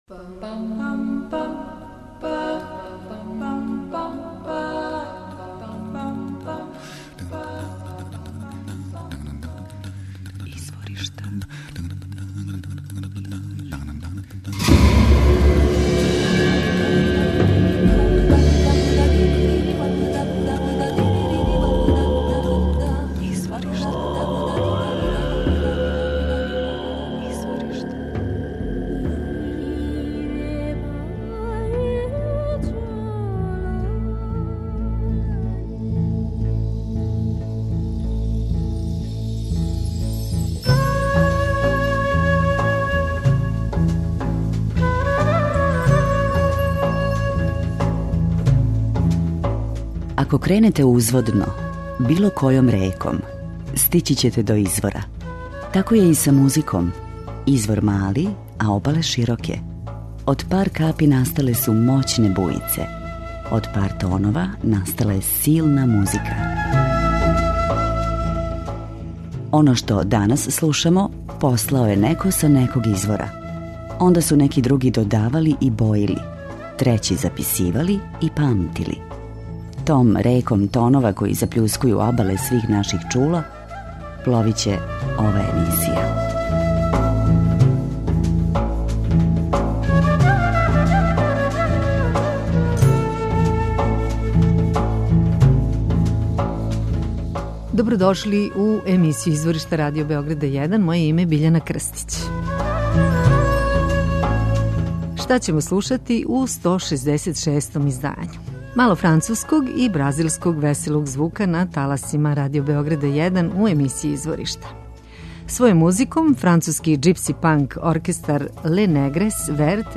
Њихова музика је енергична, са брзим ритмовима, вокалима који носе посебну енергију. У песмама користе традиционалне и акустичне инструменте, гитаре, хармонике...